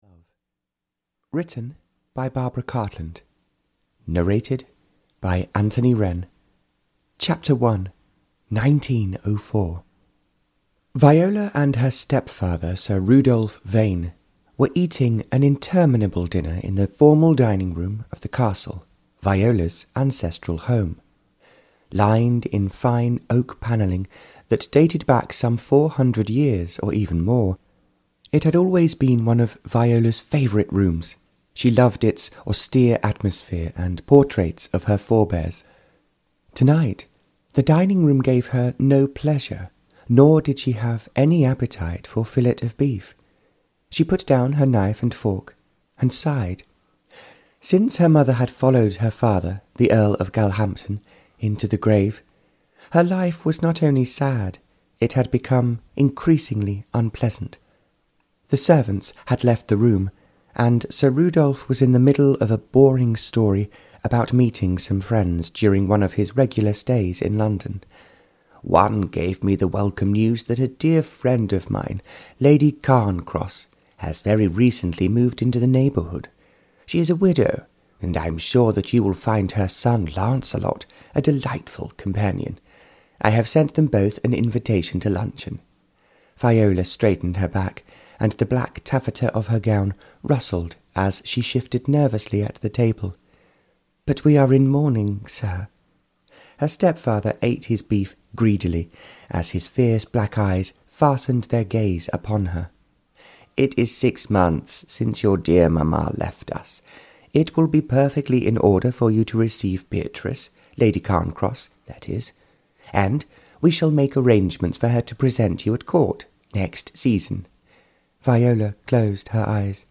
Ukázka z knihy
she-fell-in-love-barbara-cartland-s-pink-collection-153-en-audiokniha